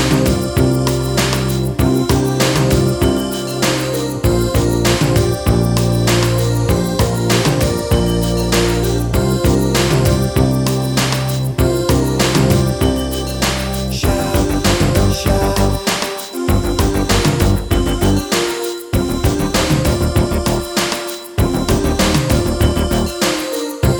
Radio Edit With No Backing Vocals Pop (1980s) 4:15 Buy £1.50